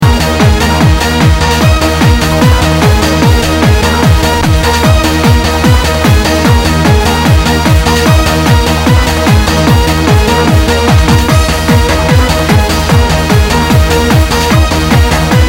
right this one has a man sayin somethink about a "CURFEW
DO NOT ATTEMPT TO THINK" etc etc.. he really goes on...